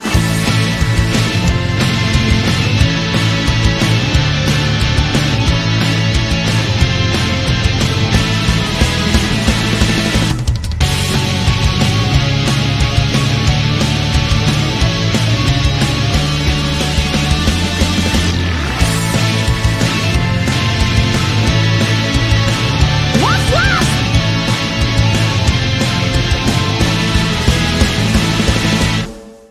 Soundtrack.